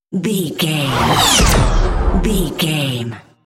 Whoosh speed with shot
Sound Effects
Atonal
dark
intense
whoosh